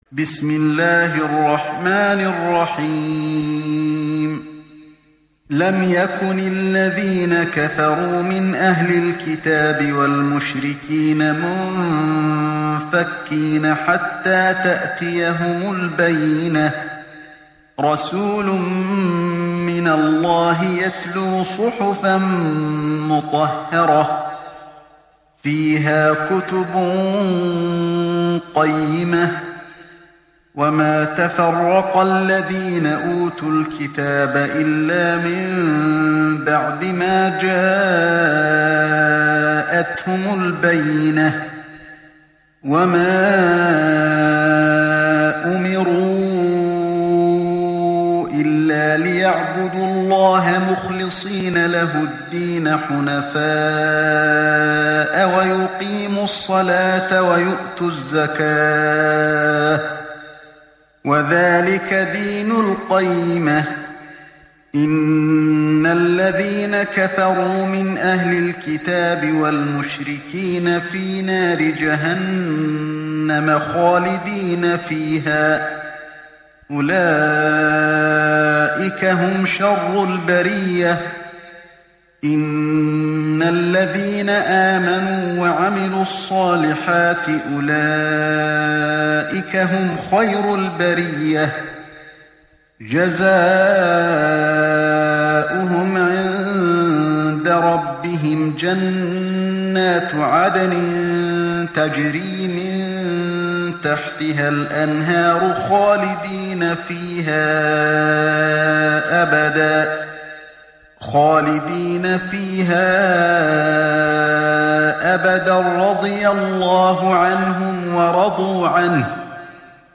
تحميل سورة البينة mp3 بصوت علي الحذيفي برواية حفص عن عاصم, تحميل استماع القرآن الكريم على الجوال mp3 كاملا بروابط مباشرة وسريعة